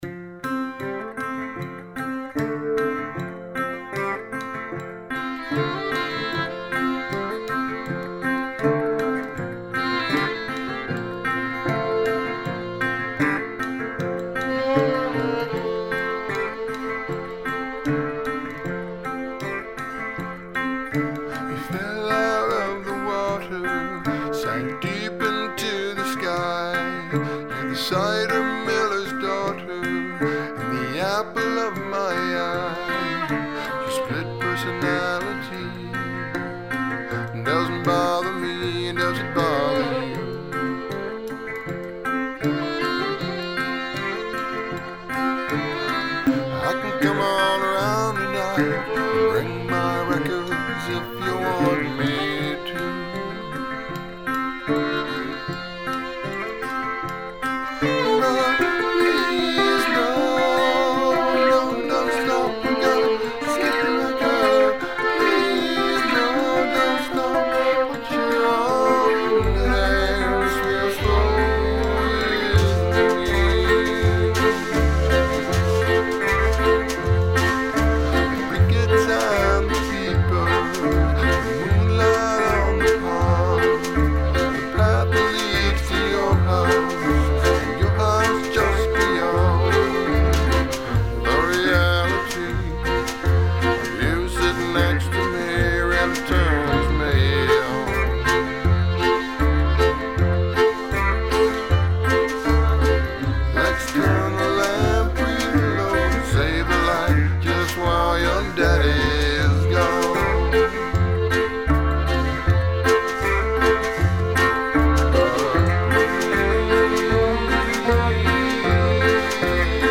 All acoustic.